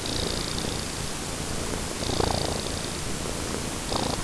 Écoutez-Moi Ronronner ! < le ronron de Peter >